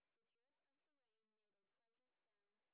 sp12_exhibition_snr20.wav